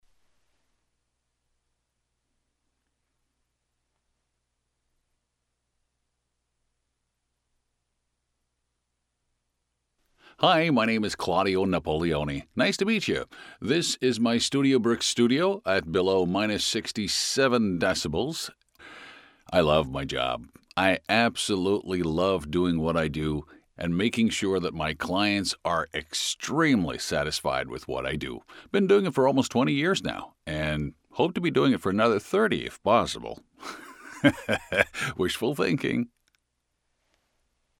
Male
American English (Native) , Canadian English (Native) , French Canadian (Native) , British English (Native) , Latin American Spanish
Approachable, Assured, Authoritative, Character, Confident, Corporate, Deep, Energetic, Engaging, Friendly, Gravitas, Reassuring, Versatile, Warm
Character, Commercial, Corporate, Documentary, Educational, E-Learning, Explainer, IVR or Phone Messaging, Narration, Podcasts, Training, Video Game
Microphone: Neumann U87
Audio equipment: StudioBricks Booth, Summit TLA-50, summit 2Ba-221, source connect, Cleenfeed